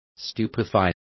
Complete with pronunciation of the translation of stupefy.